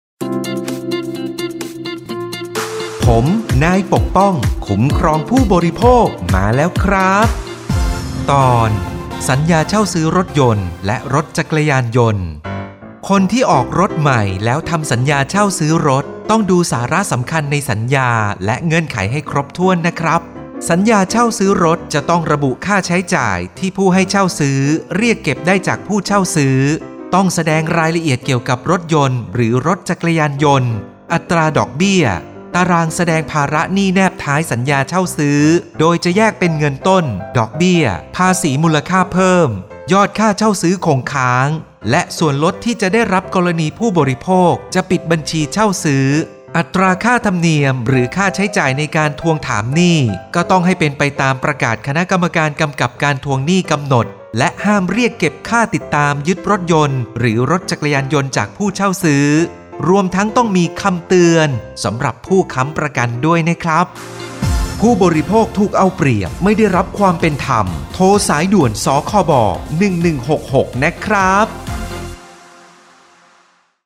สื่อประชาสัมพันธ์ MP3สปอตวิทยุ ภาคกลาง
012.สปอตวิทยุ สคบ._ภาคกลาง_เรื่องที่ 12_.mp3